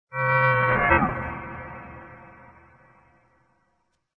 Descarga de Sonidos mp3 Gratis: campana 2.